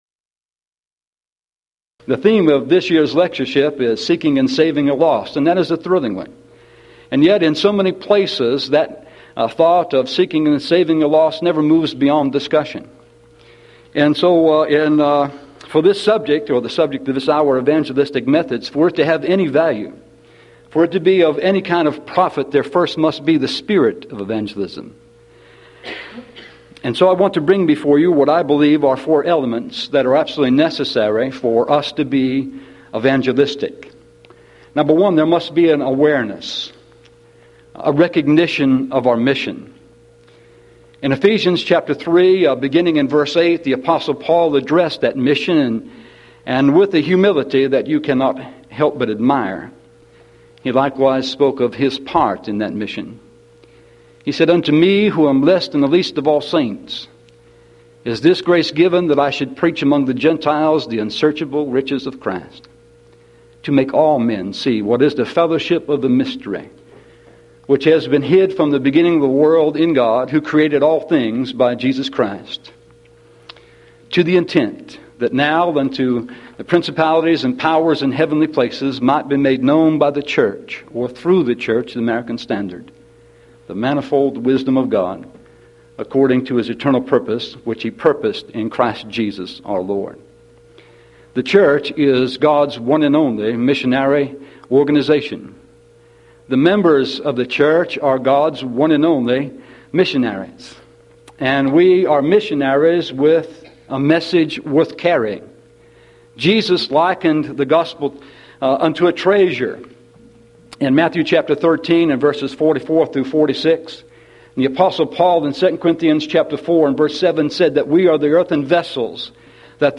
Event: 1994 Mid-West Lectures Theme/Title: To Seek and Save the Lost
lecture